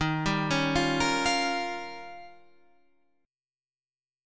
D# 9th